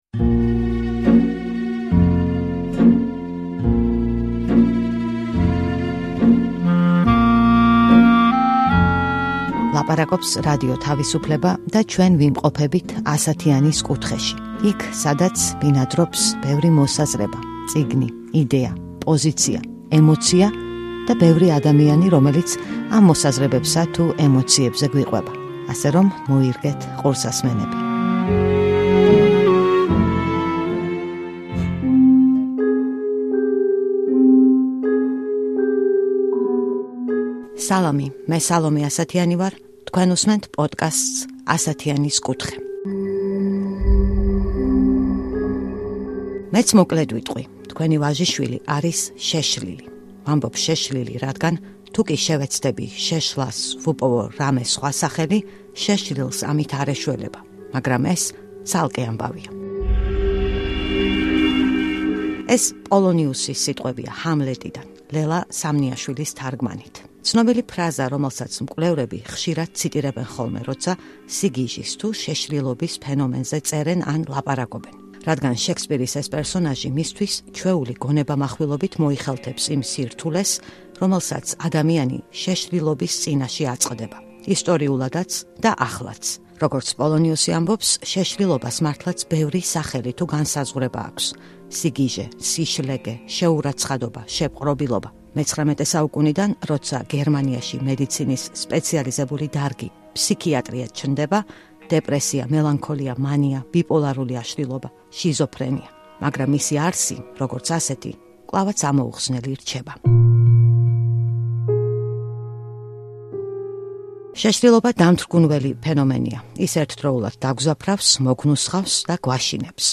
ეპიზოდში მოგიყვებით ორ პარალელურ ისტორიას - ფსიქიატრიის, როგორც დარგის ამბავს, და იმას, თუ როგორ ესმოდათ ადამიანებს სიგიჟის ფენომენი სხვადასხვა ეპოქაში, როგორ აღწერდნენ მას ხელოვნებაში. ჩვენი სტუმარია ენდრიუ სკალი, რომელსაც ფსიქიატრიის ისტორიის მკვლევრებს შორის ერთ-ერთ უდიდეს ავტორიტეტად მიიჩნევენ.